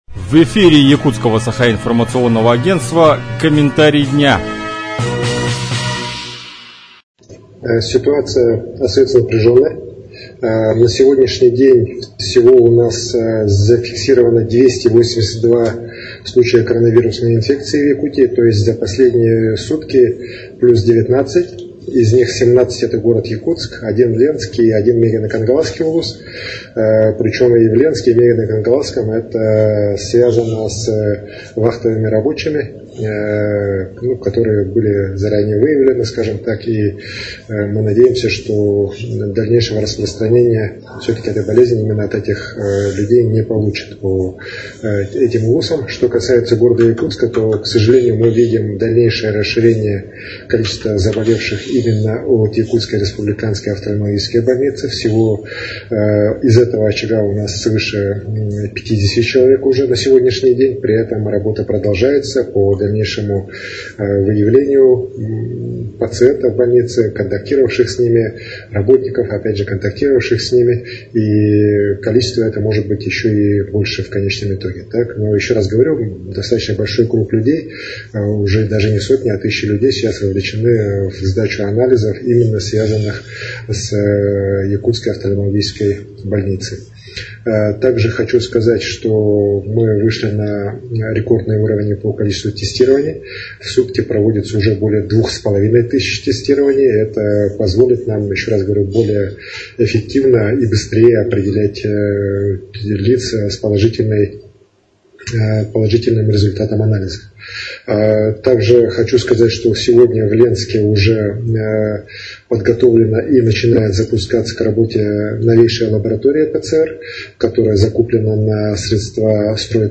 Какова обстановка в Якутии на 3 мая, рассказал глава региона Айсен Николаев.